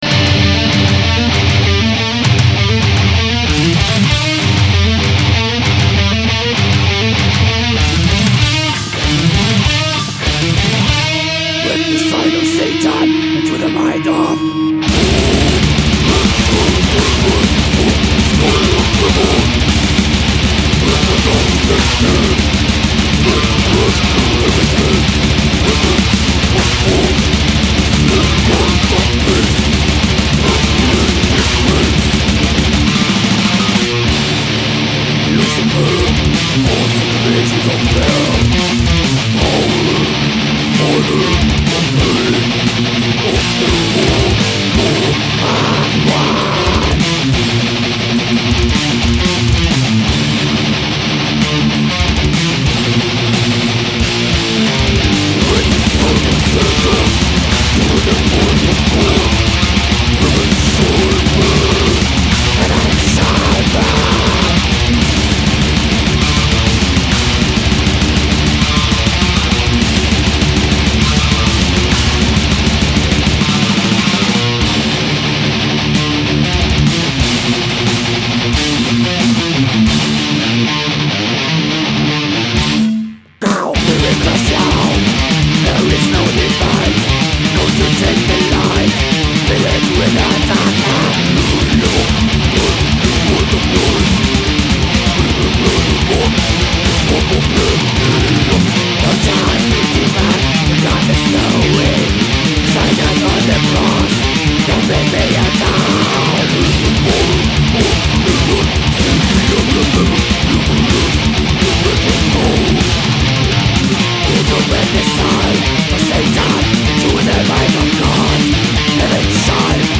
Es handelt sich hier um Death Metal.
Dieses Gitarren Duo (?) gegen Ende find ich geil.
Wirklich professionell und sauber gespielt und aufgenomm.
Nee, ist eigentlich klassischer Ami Death.